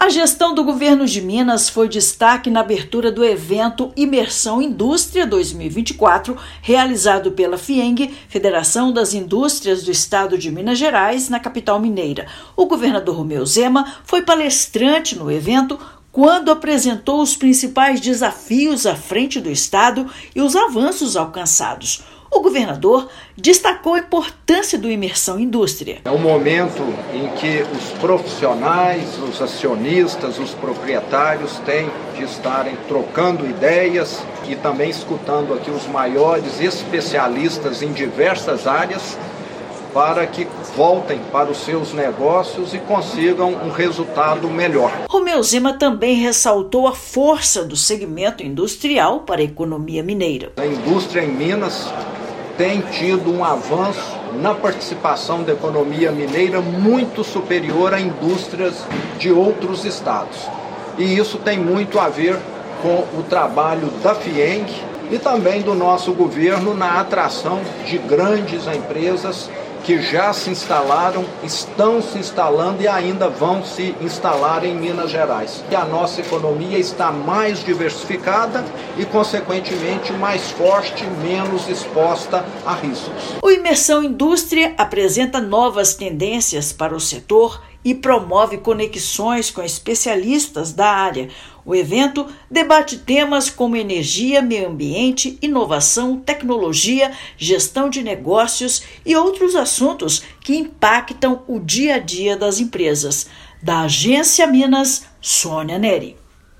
Avanços no desenvolvimento da economia do estado foram temas abordados em palestra ministrada pelo governador. Ouça matéria de rádio.